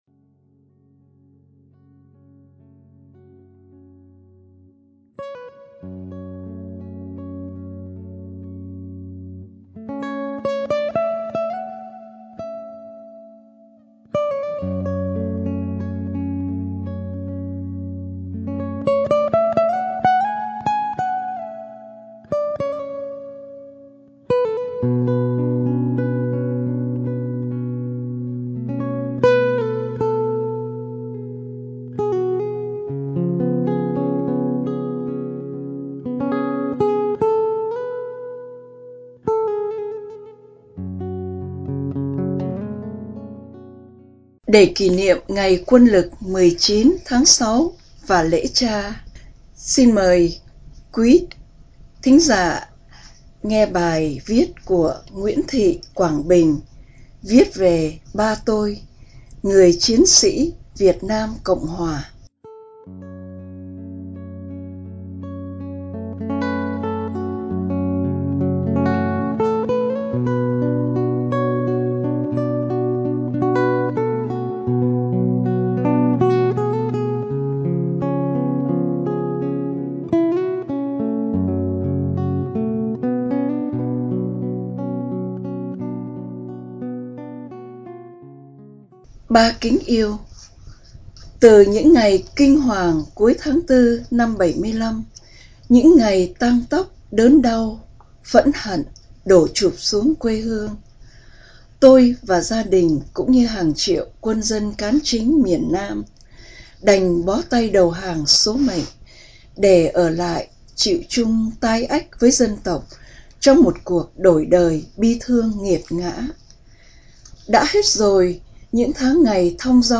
Đọc Truyện Chọn Lọc -Câu Chuyện Viết Về Ba Tôi